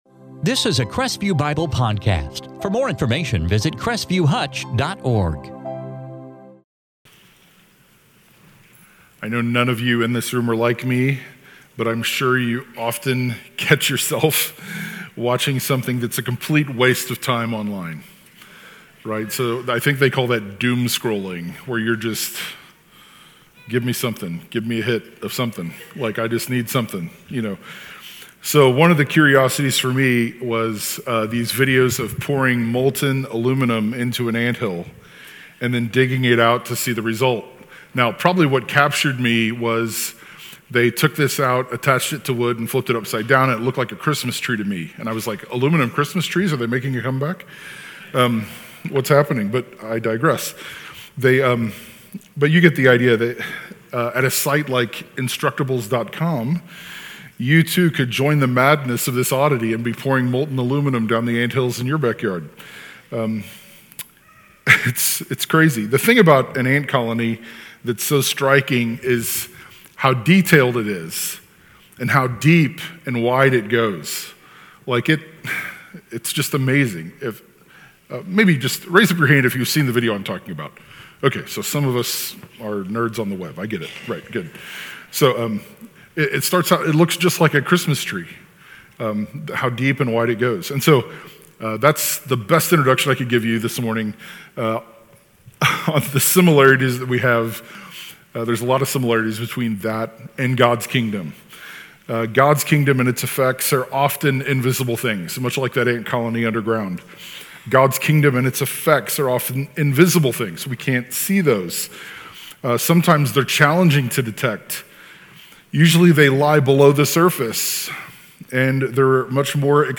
sermon 8/4/24